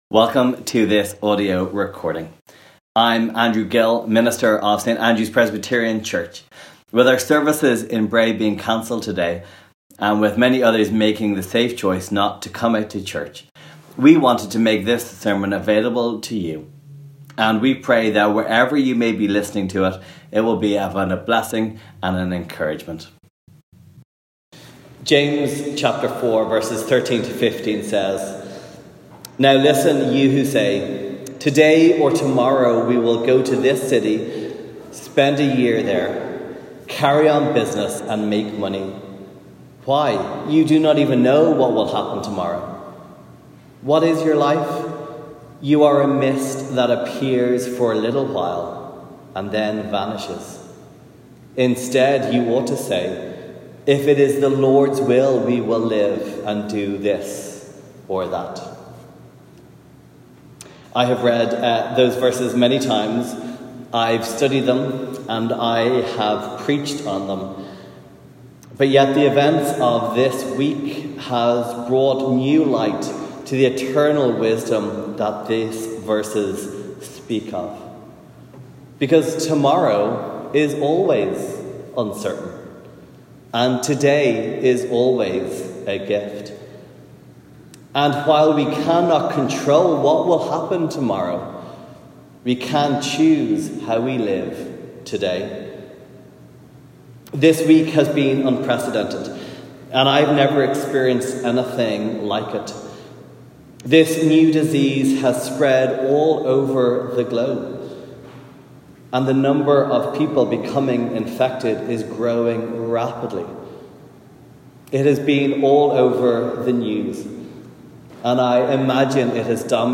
Recording of this morning’s sermon from Psalm 91 as we think about how to respond to the Covid-19 pandemic.